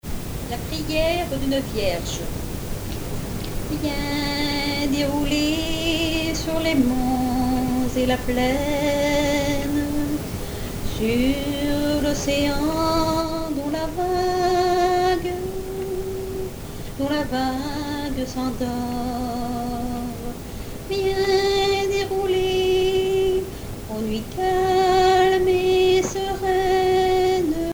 Genre strophique
chansons de variété et traditionnelles
Pièce musicale inédite